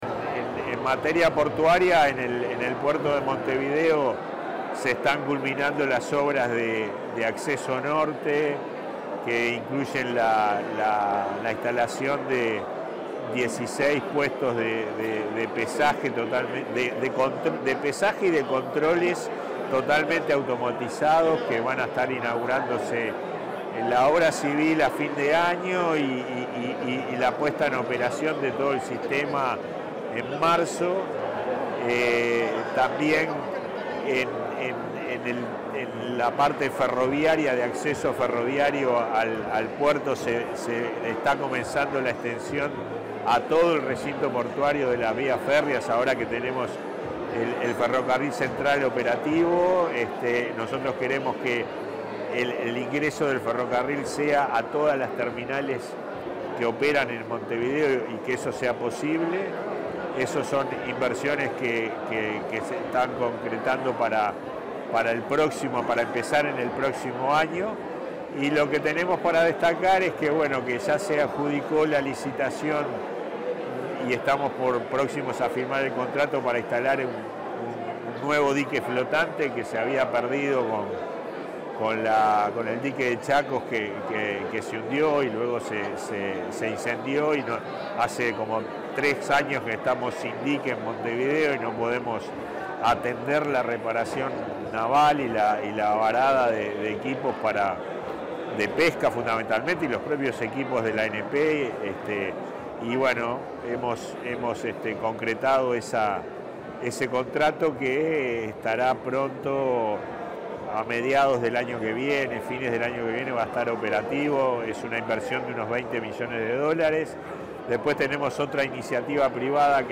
Declaraciones del presidente de la ANP, Pablo Genta